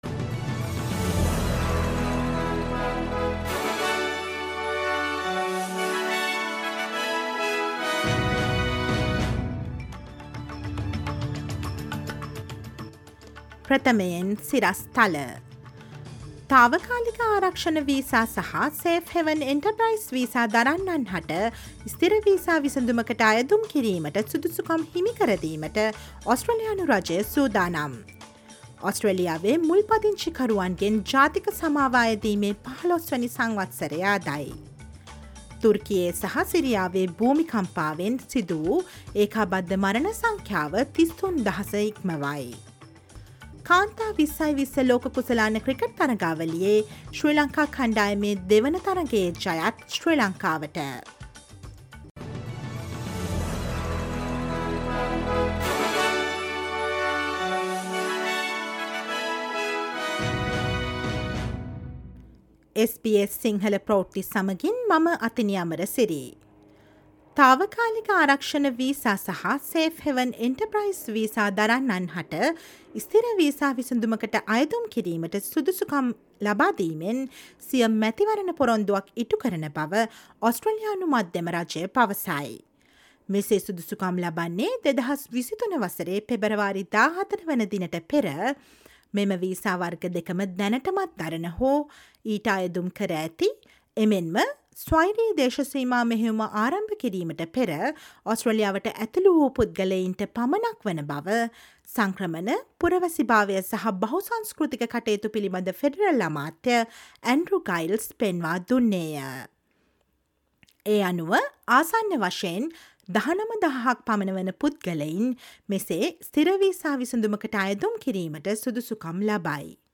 ඔස්ට්‍රේලියාවේ නවතම පුවත් , විදෙස් පුවත් සහ ක්‍රීඩා පුවත් රැගත් SBS සිංහල සේවයේ 2023 පෙබරවාරි 13 සඳුදා ප්‍රවෘත්ති ප්‍රකාශයට සවන් දෙන්න